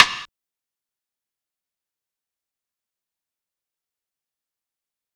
Crash Perc.wav